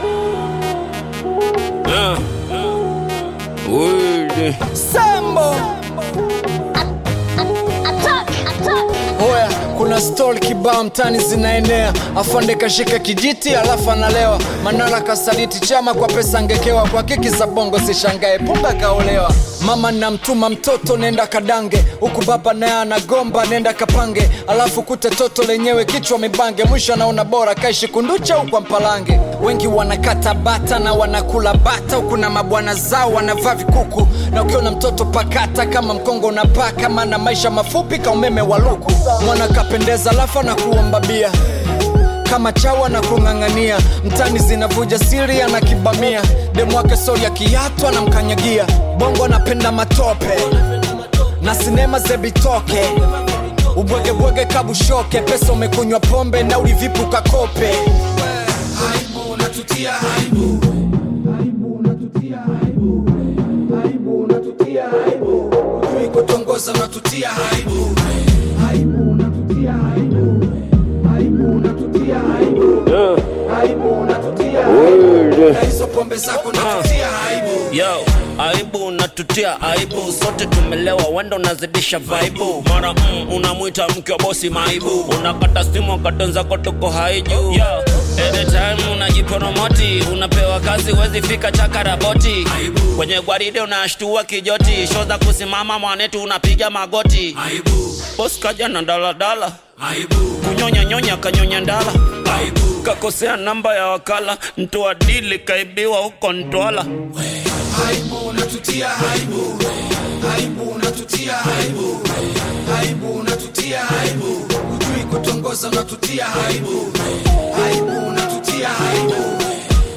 Tanzanian bongo flava
Hip-Hop